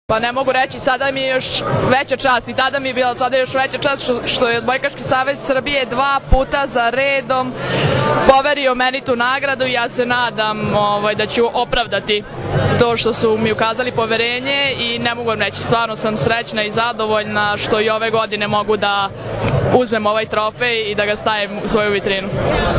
Odbojkaški savez Srbije je danas u beogradskom hotelu “Metropol Palas” organizovao Novogdišnji koktel “Naša strana mreže”, na kojem su dodeljeni Trofeji “Odbojka spaja”, “Budućnost pripada njima”, Trofeji za najbolju odbojkašicu i odbojkaša i najbolju odbojkašicu i odbojkaša na pesku, kao i prvi put “specijalna plaketa OSS”.
IZJAVA